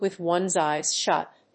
アクセントwith one's éyes shút [clósed]